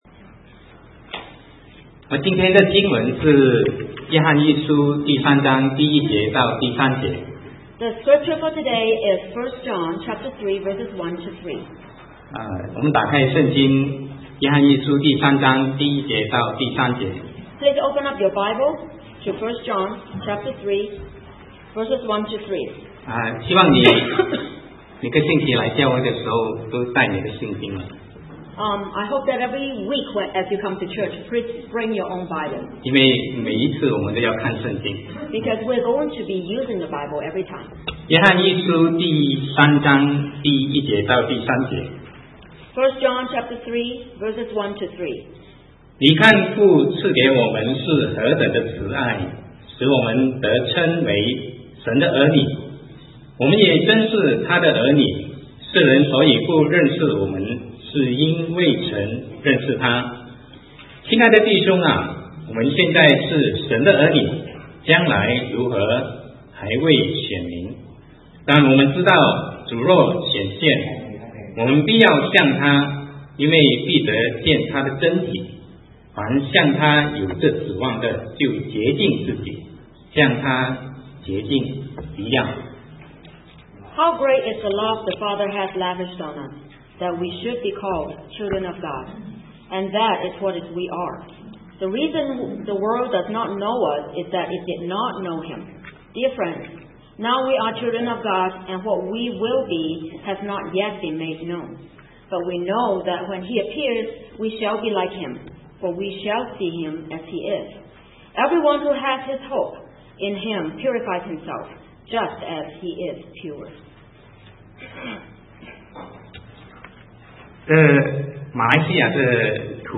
Sermon 2009-10-18 Privilege Comes with Responsibility